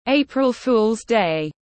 Ngày cá tháng tư tiếng anh gọi là April Fool’s Day, phiên âm tiếng anh đọc là /ˈeɪprəl fuːlz deɪ/
April Fool’s Day /ˈeɪprəl fuːlz deɪ/